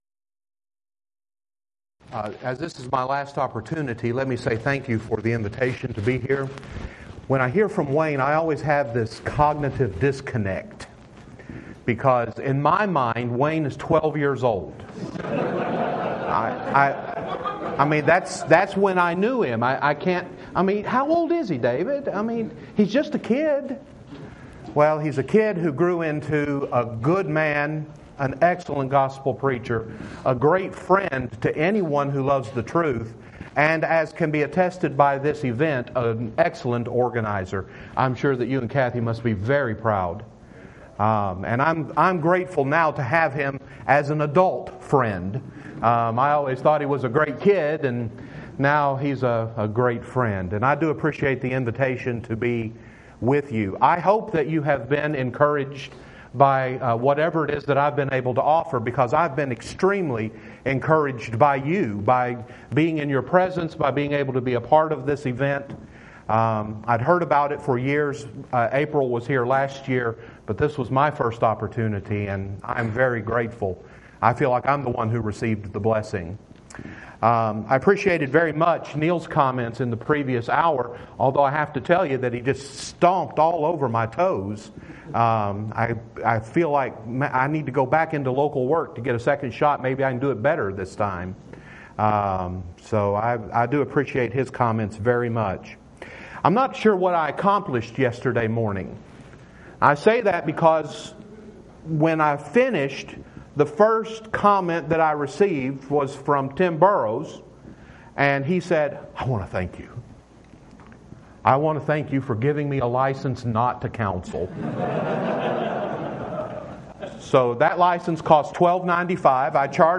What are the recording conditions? Event: 2017 Focal Point Theme/Title: Preacher's Workshop